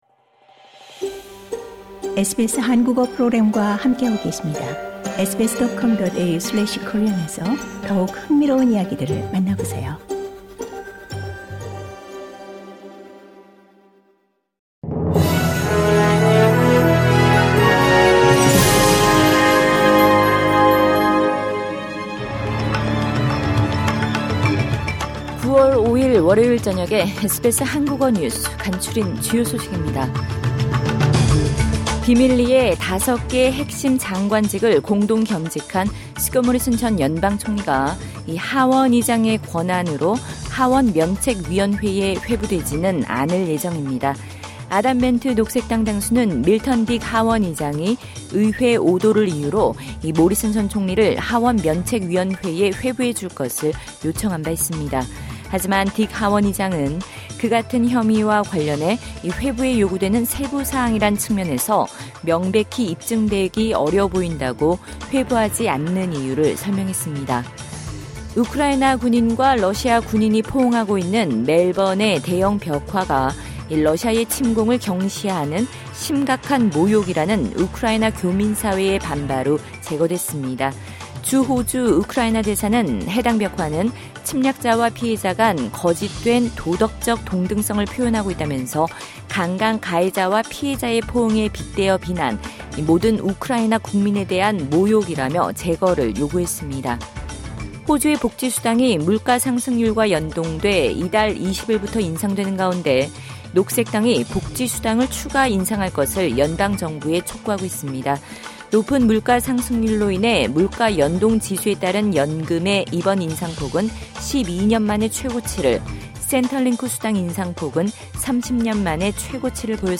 2022년 9월 5일 월요일 저녁 SBS 한국어 간추린 주요 뉴스입니다.